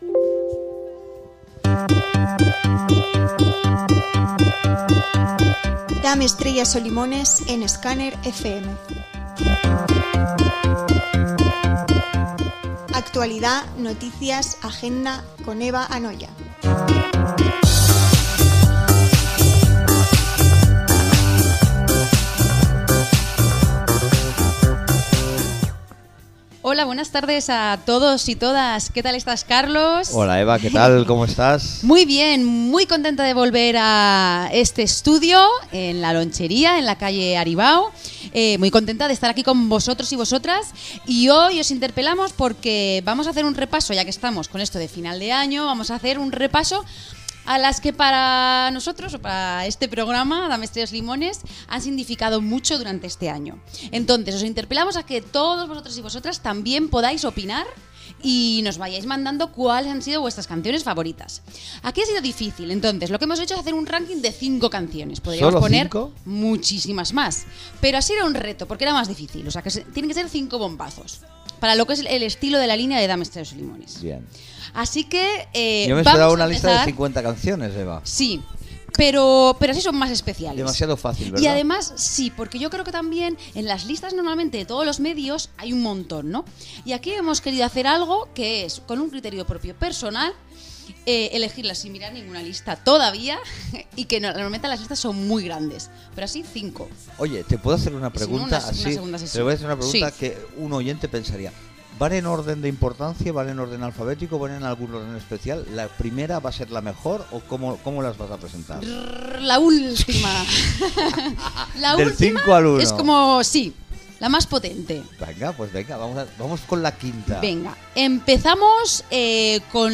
Careta del programa, presentació del programa dedicat a les cinc millors cançons de l'any 2022.